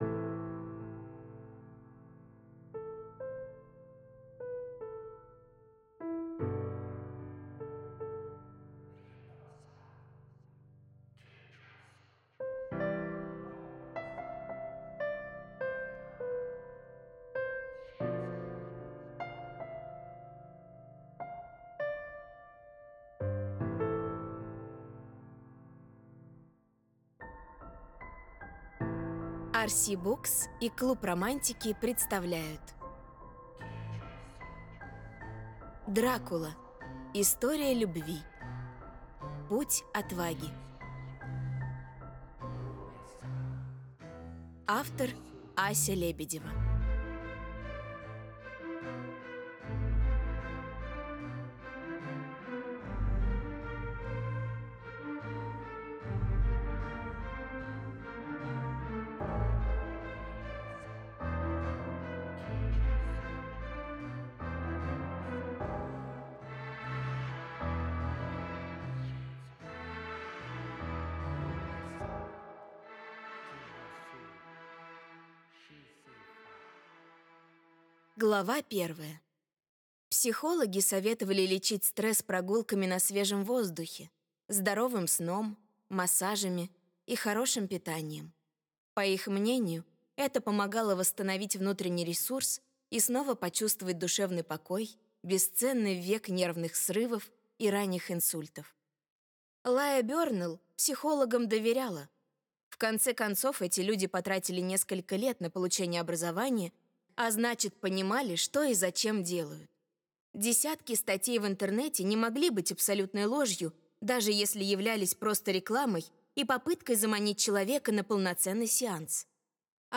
Проект озвучен Творческим объединением Le Voice ("Левойс") для издательства RC Books в 2025 году.
Обязательно куплю еще, особенно подкупают музыкальные эффекты во время прослушивания.